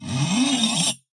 描述：金属效果使用台虎钳固定锯片和一些工具来击打，弯曲，操纵。 所有文件都是96khz 24bit，立体声。
Tag: 研磨 尖叫 金属 耐擦 效果 声音